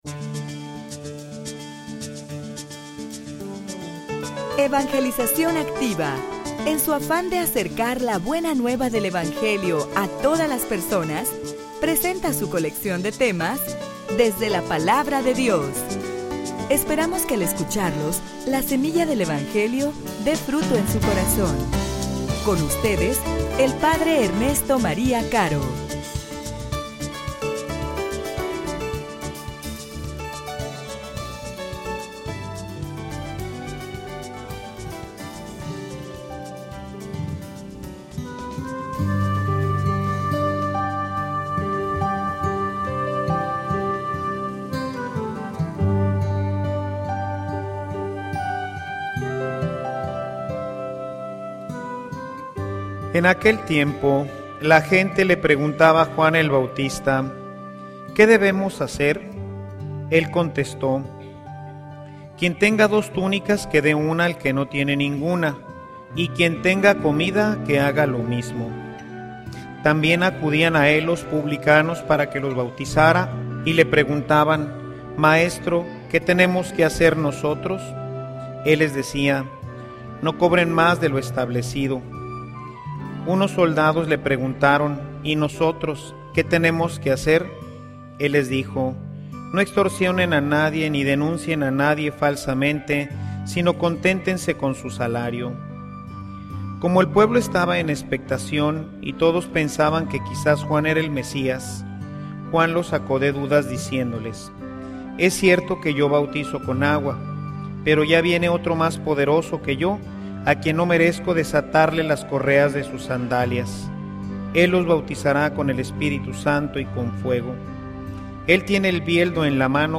homilia_Tenemos_razones_para_estar_alegres.mp3